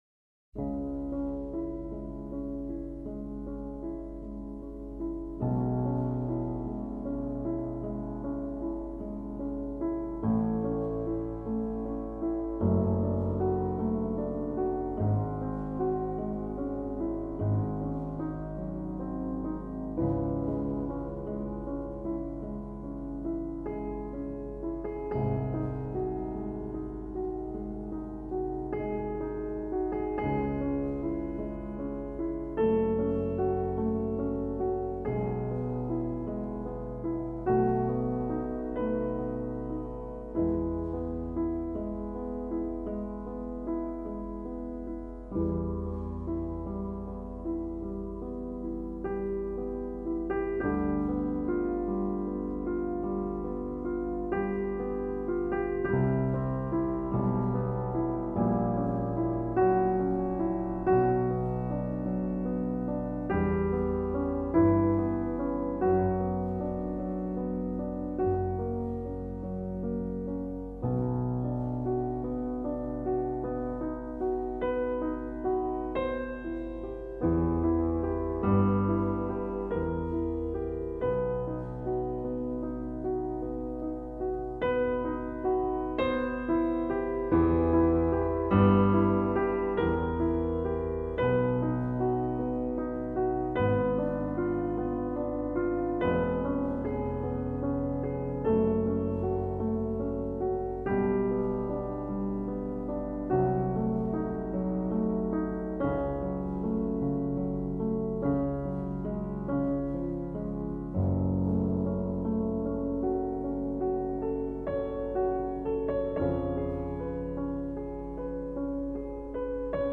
Voicing: Solo Piano